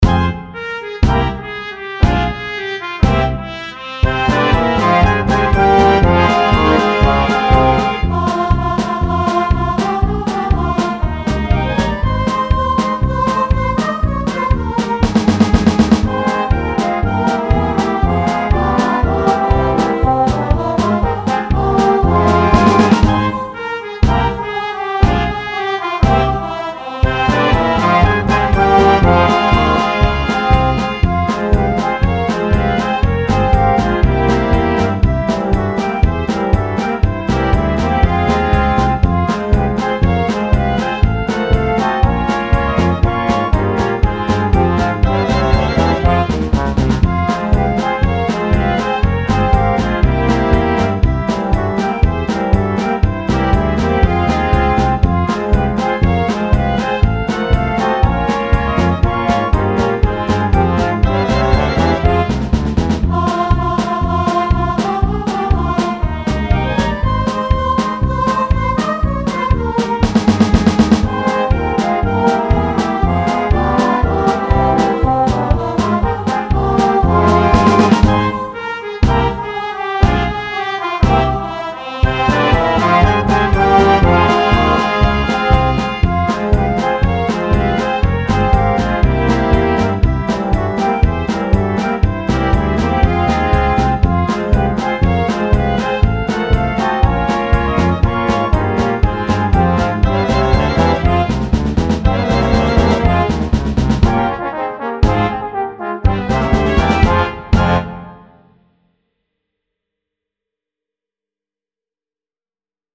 Dychová hudba Značky
Spievané polky Zdieľajte na